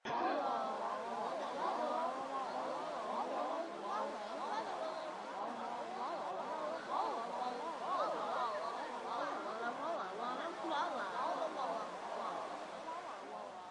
古巴 " 人群中的孩子们公园广场上的沉重的瓦拉，古巴哈瓦那，2008年
描述：人群分钟中等孩子公园广场重瓦拉哈瓦那，古巴2008年
Tag: 分机 公园 古巴 孩子 人群 方形 沃拉